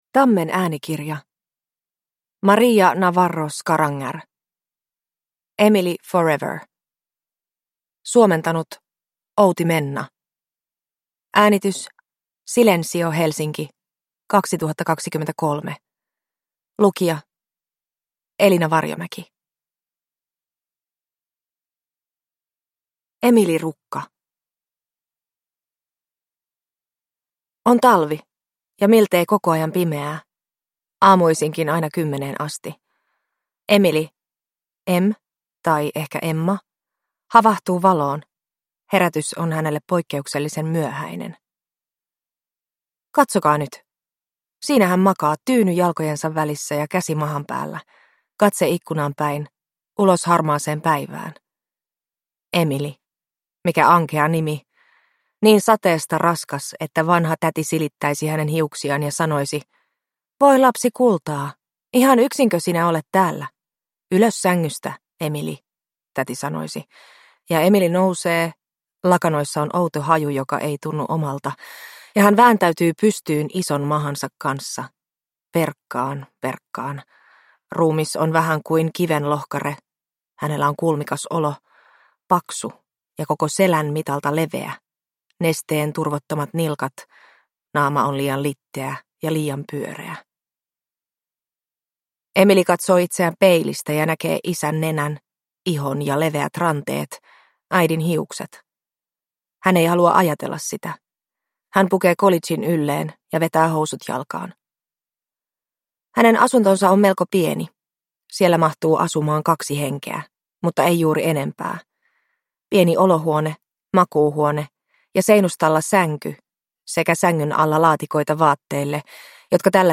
Emily forever – Ljudbok – Laddas ner